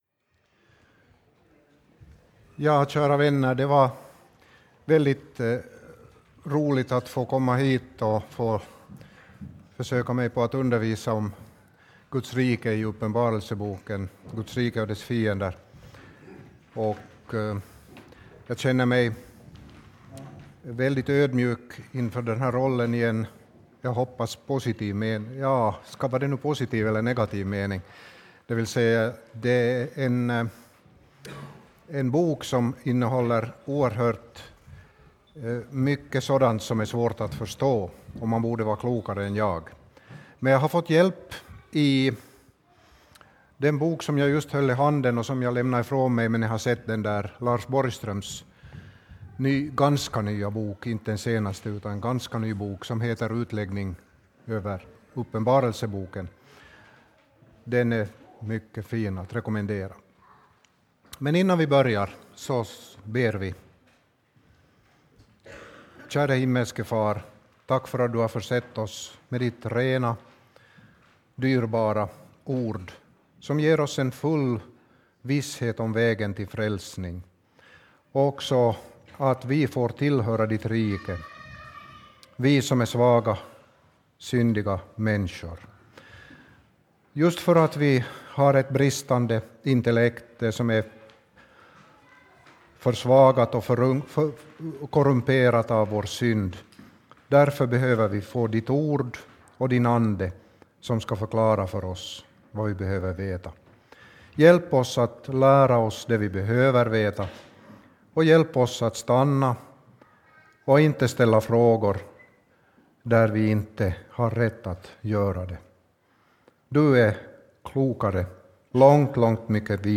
Föredrag
på lägergården Fridhem, Vännäs.